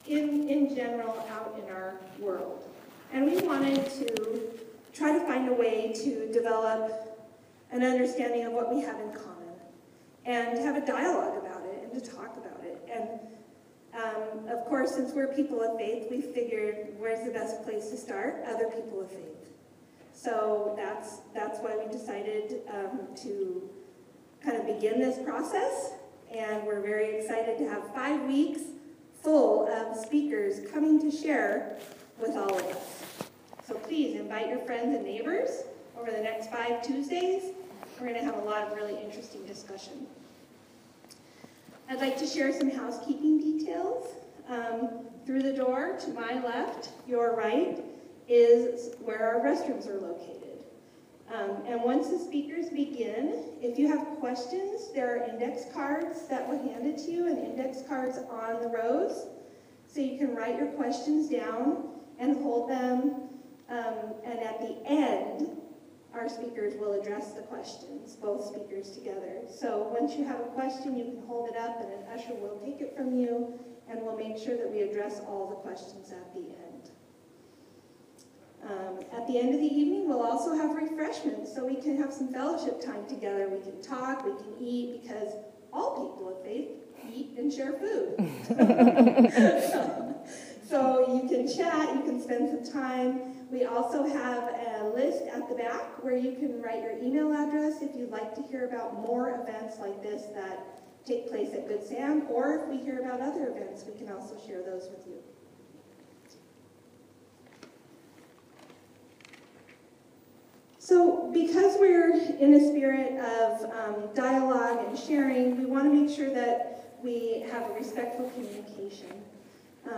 8:00 PM Place : Inclusion Group of Good Samaritan United Methodist Church (19624 Homestead Rd., Cupertino, CA) Description